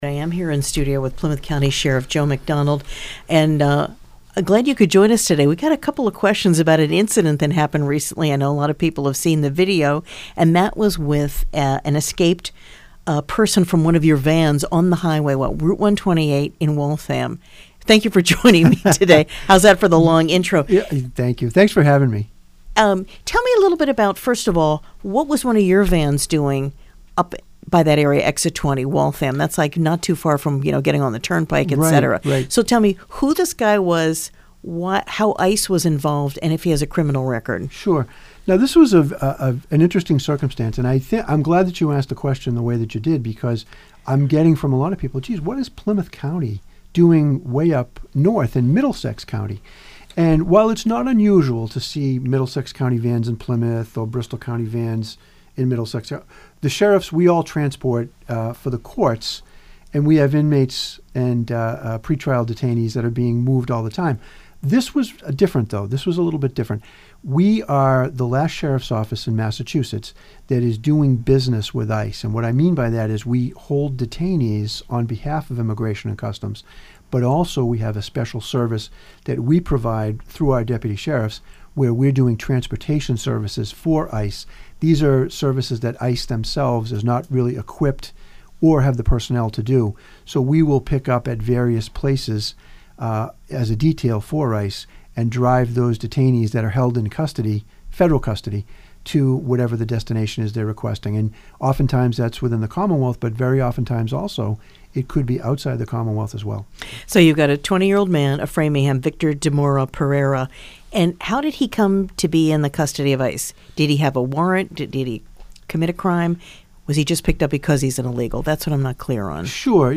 Plymouth County Sheriff Joe McDonald speaks